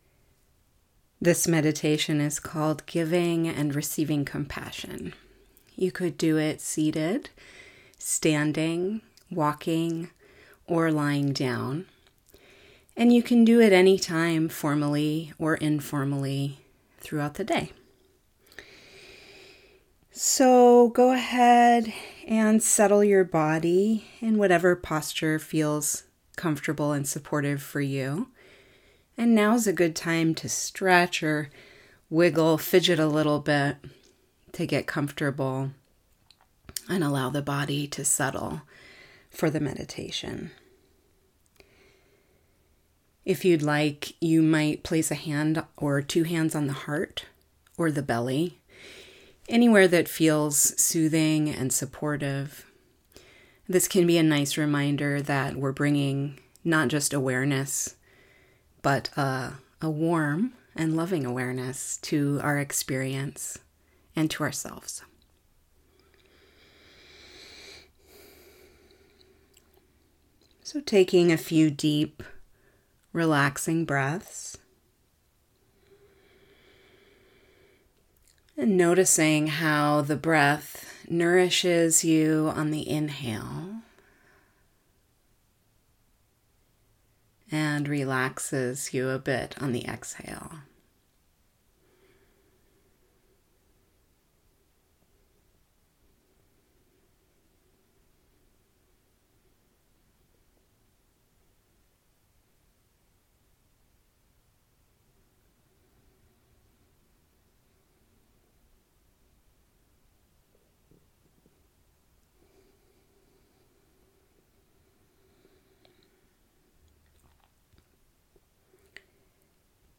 This core meditation for our Mindful Self-Compassion program connects compassion practice with a feeling of equanimity. Loosely based on the Tibetan Buddhist Tonglen meditation, Giving and Receiving Compassion involves inviting the feeling of receiving all that you need on the inhale and giving to others on the exhale. It supports a more balanced approach to receiving and giving.